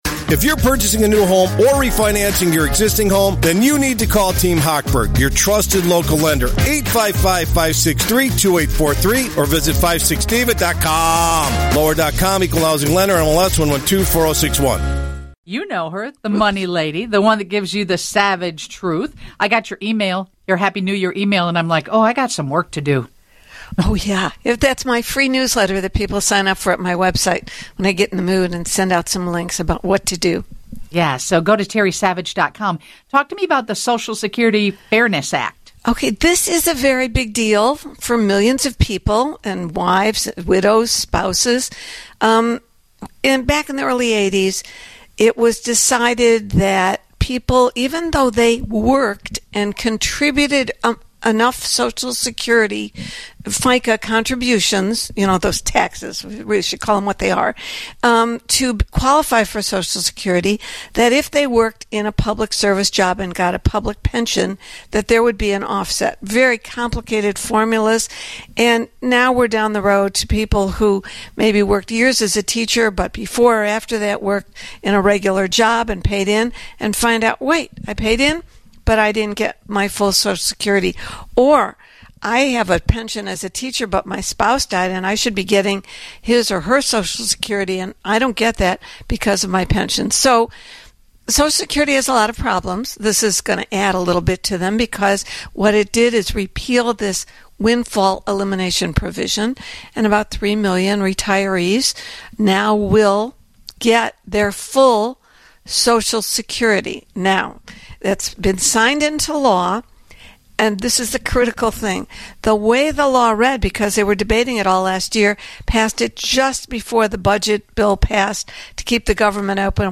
Plus, she answers a number of questions from listeners.